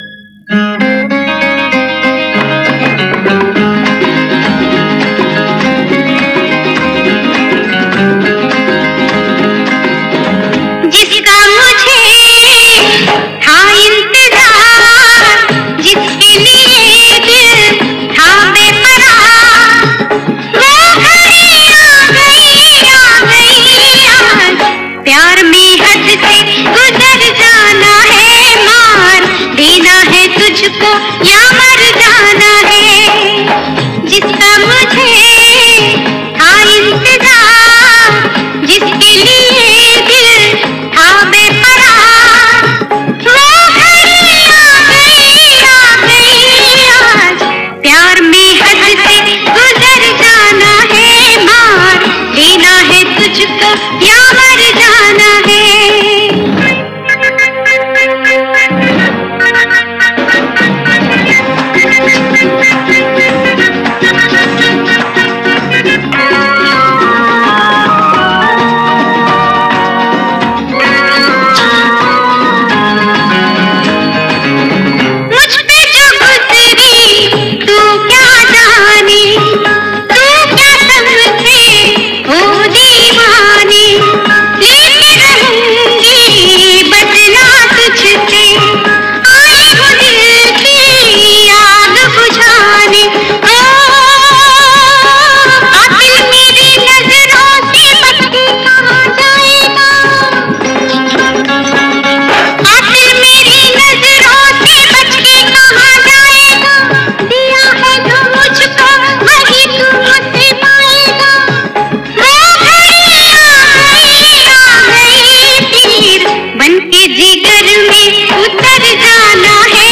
Category: HIGH GAIN